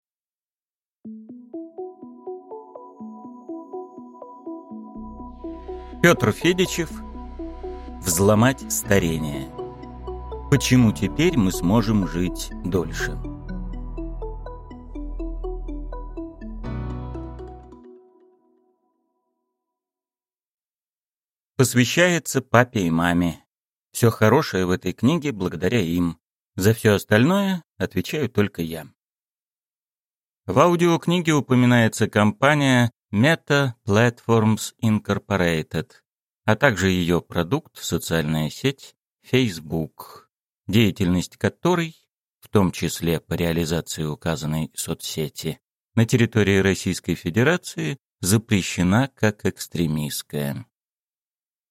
Аудиокнига Взломать старение: Почему теперь мы сможем жить дольше | Библиотека аудиокниг